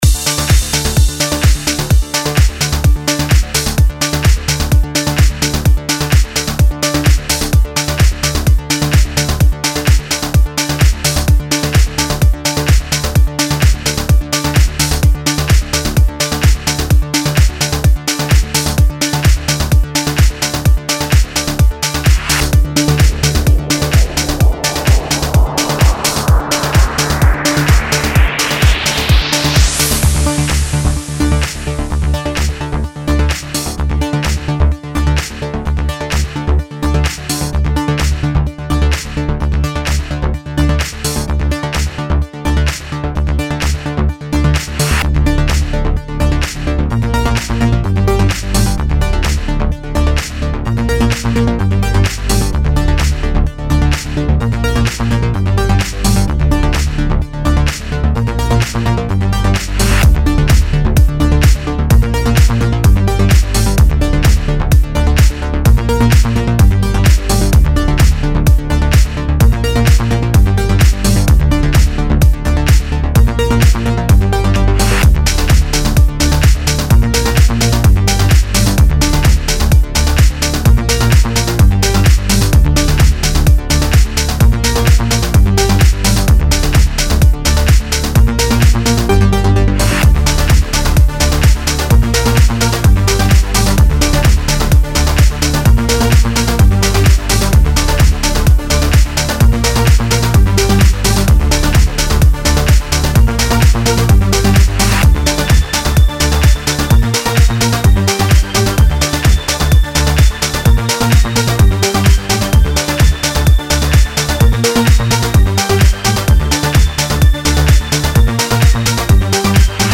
C'est dans un style très "progressif"
J'aime beaucoup le 2e break.